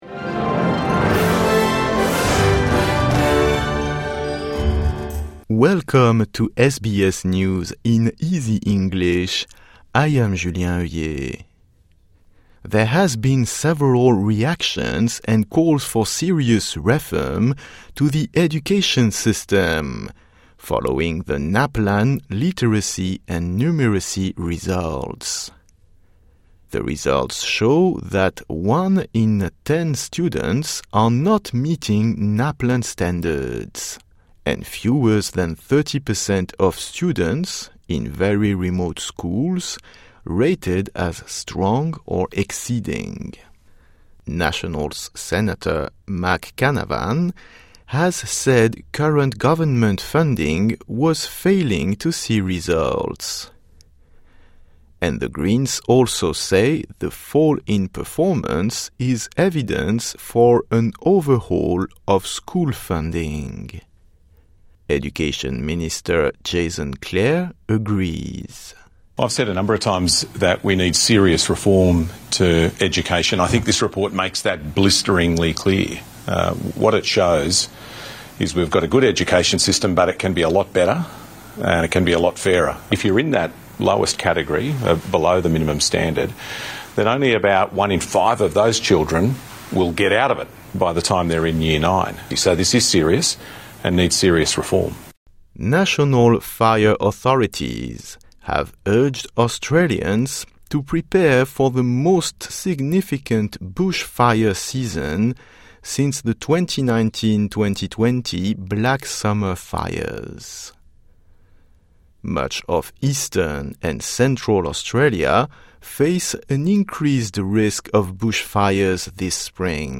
A daily 5 minute news wrap for English learners and people with disability.